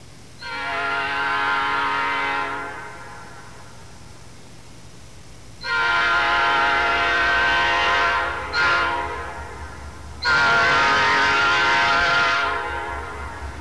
Horn bells cast from both the new and old patterns have found their way onto new P5's in various combinations, resulting in a wide variety of different, often dischordant, sounds.
Norfolk Southern, various locomotives: